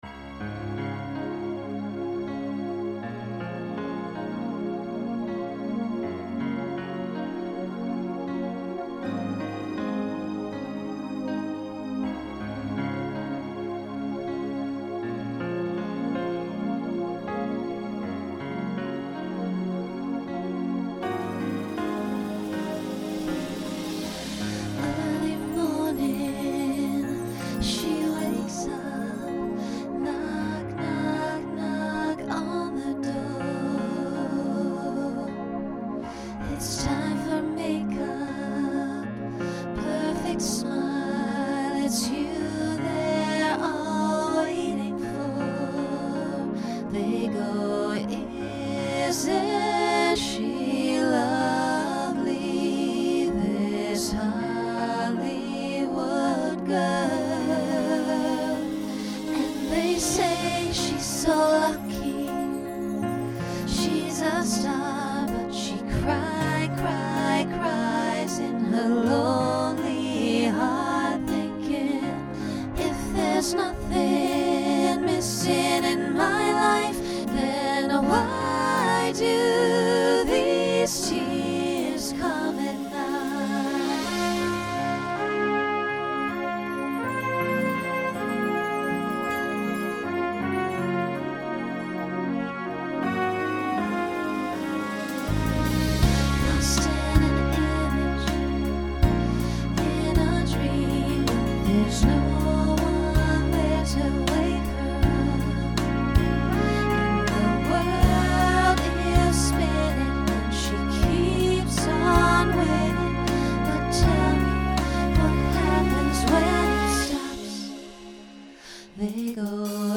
Genre Pop/Dance
Show Function Ballad Voicing SSA